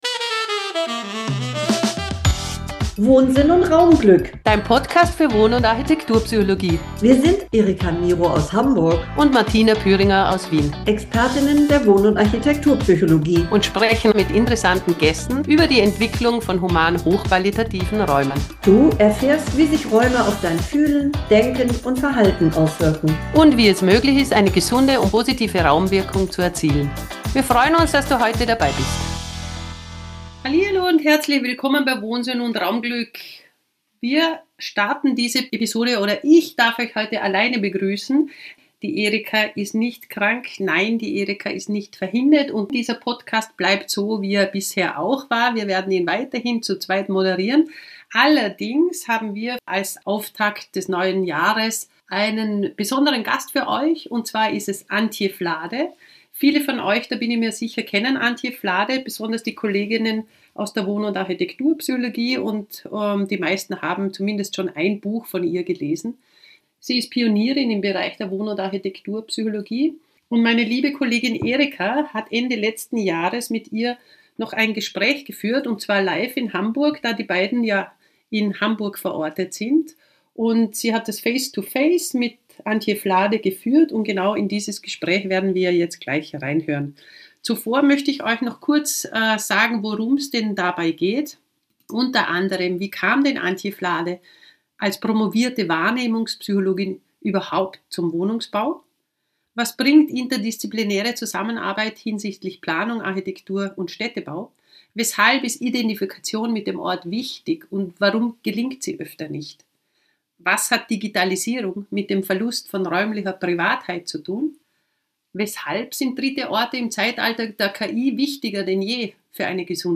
Sie meint, Stadtentwicklung wird immer wichtiger! Freu dich auf das interessante Gespräch!